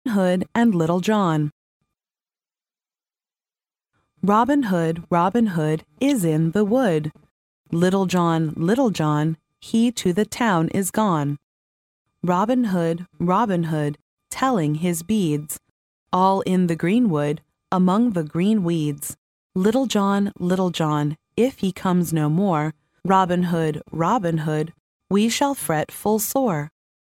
幼儿英语童谣朗读 第14期:罗宾汉和小约翰 听力文件下载—在线英语听力室